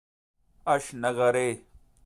pronounce; Urdu: ہشتنگری;